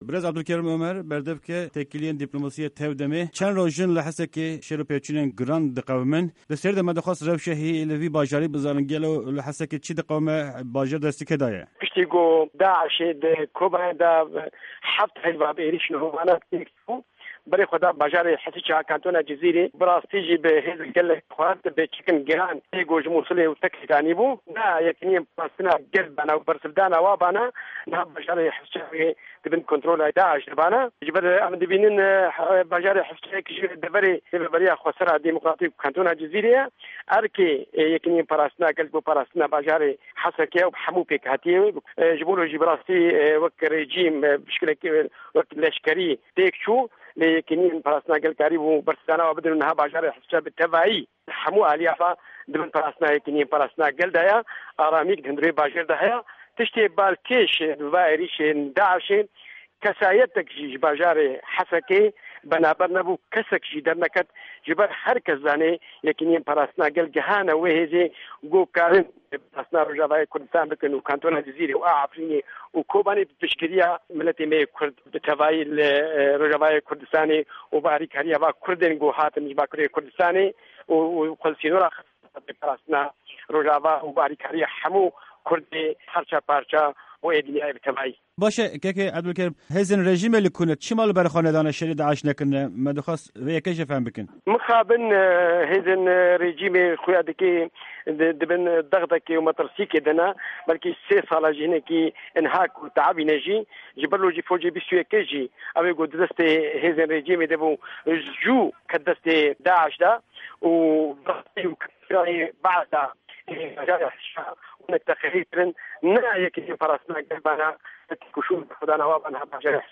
Di hevpeyvîneke taybet de